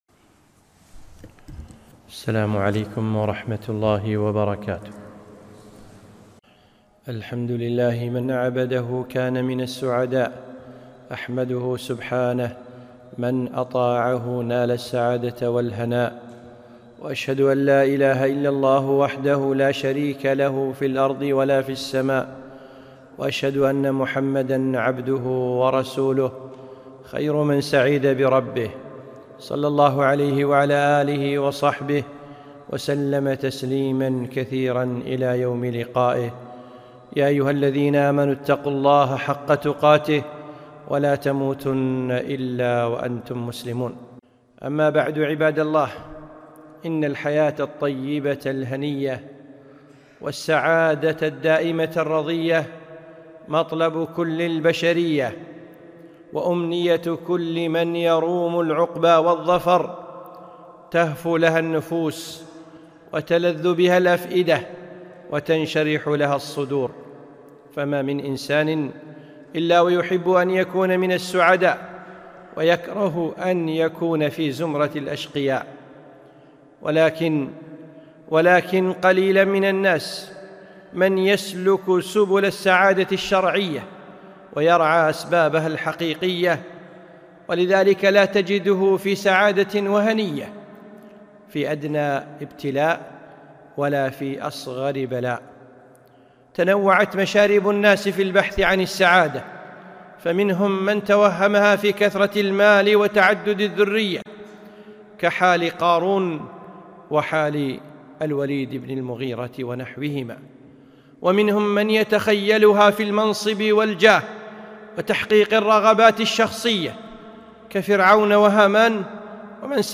خطبة - السعادة الحقيقية